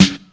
Royality free snare single hit tuned to the F# note. Loudest frequency: 1203Hz
• Old School Warm Hip-Hop Acoustic Snare Sound F# Key 08.wav
old-school-warm-hip-hop-acoustic-snare-sound-f-sharp-key-08-mV4.wav